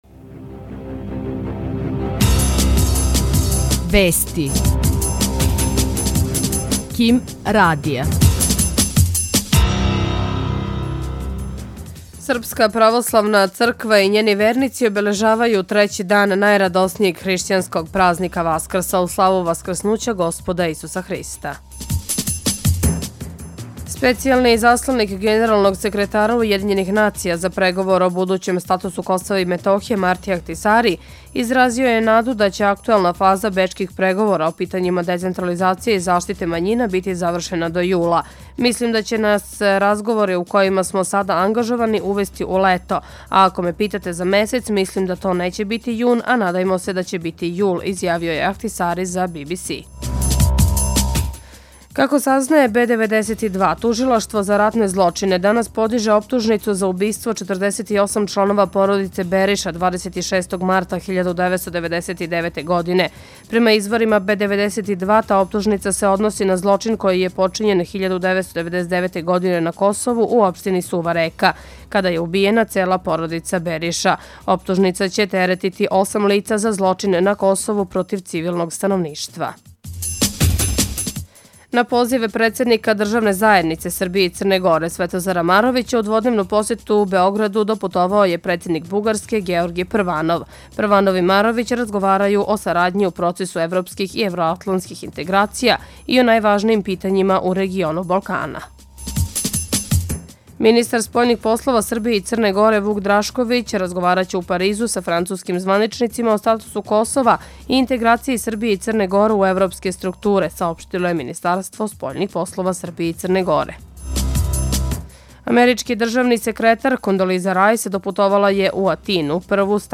Experimental